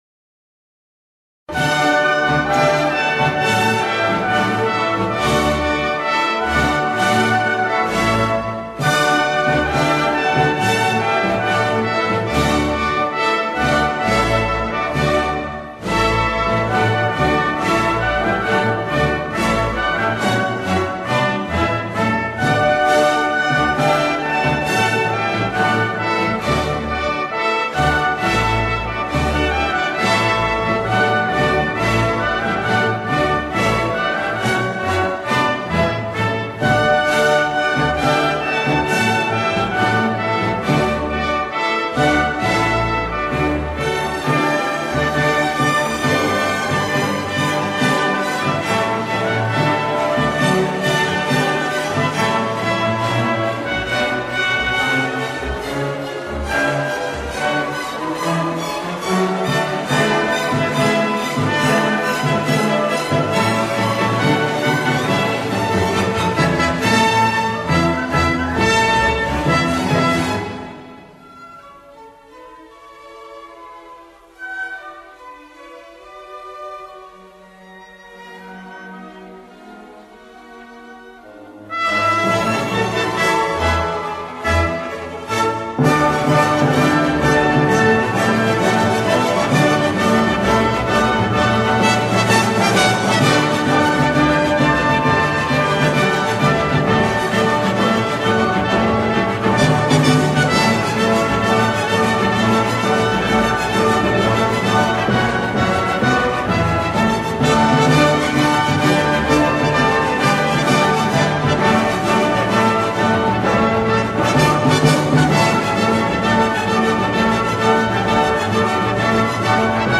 Per noi coniglietti è stato abbastanza facile dire cosa ci rende felici e ci siamo divertiti a dipingere ascoltando solo musica allegra.